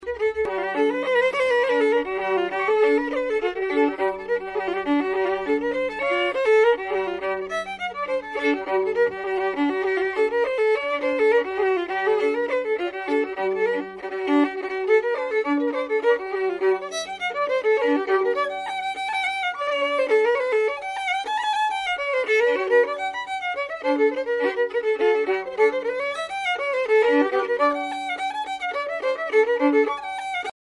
Fiddle
REELS
Recorded in Camden Town, London,